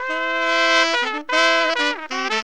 HORN RIFF 30.wav